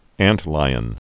(ăntlīən)